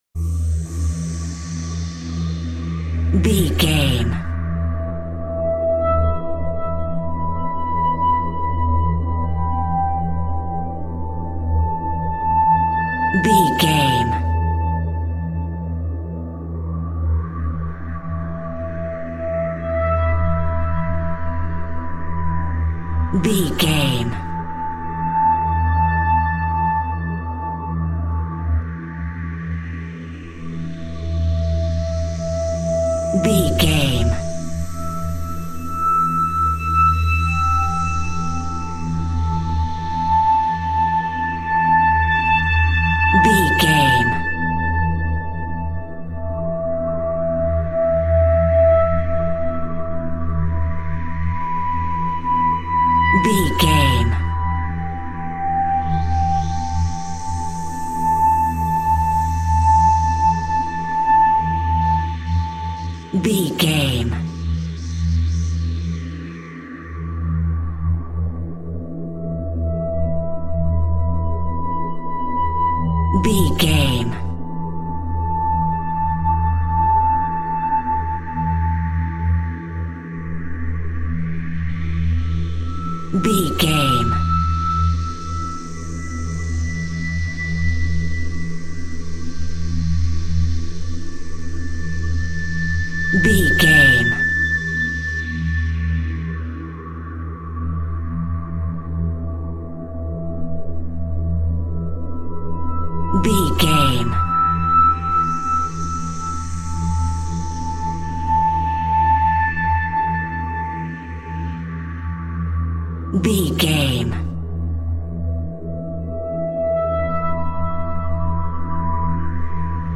Aeolian/Minor
ominous
dark
haunting
eerie
synthesizer
spooky
Horror synth
Horror Ambience
electronics